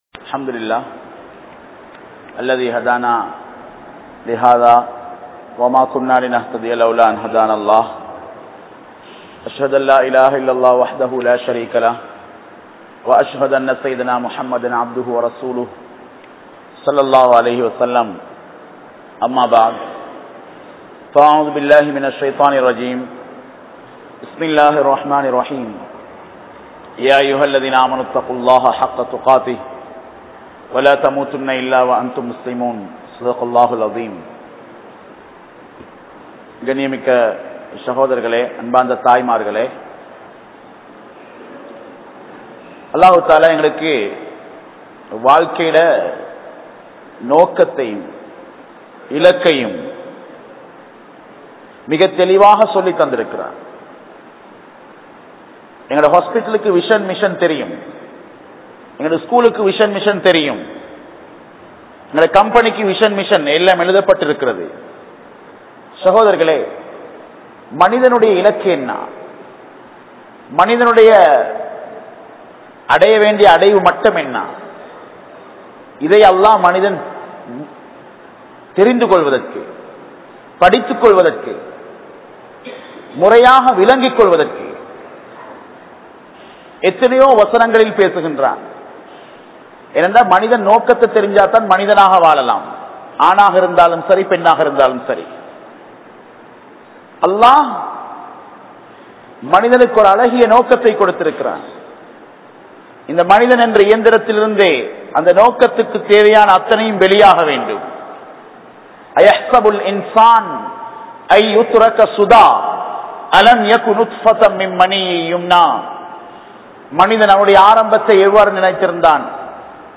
Kanavanukku Maaru Seium Pengal (கணவனுக்கு மாறு செய்யும் பெண்கள்) | Audio Bayans | All Ceylon Muslim Youth Community | Addalaichenai
Galle,Gintota, Hussain Jumua Masjith